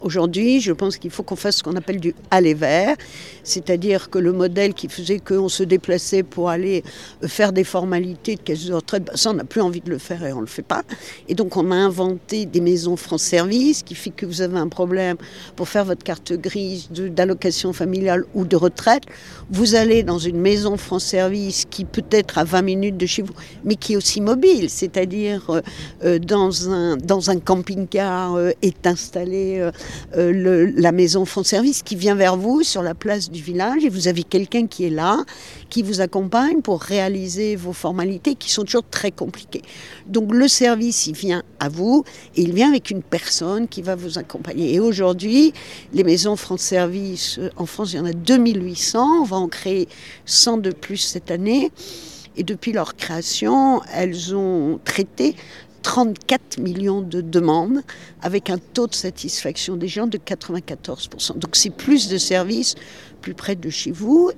Un département plein de ressources a-t-elle réaffirmé à notre micro.
Françoise Gatel